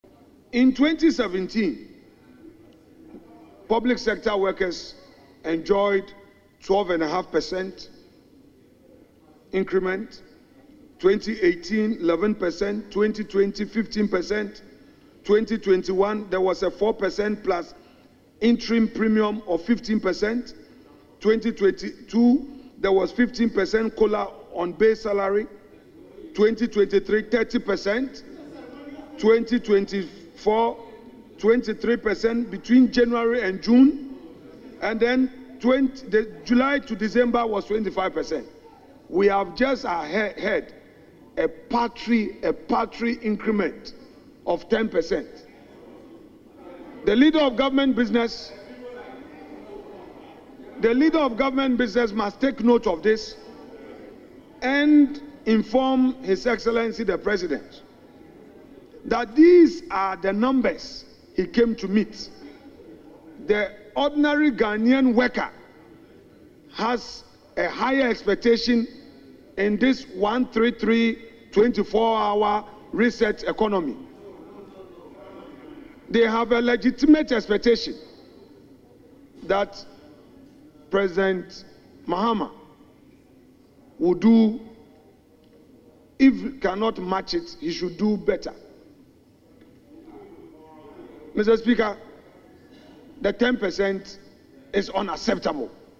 However, addressing Parliament on February 21, Mr. Afenyo-Markin argued that the previous Akufo-Addo administration handled salary adjustments more effectively, offering higher increments over the years.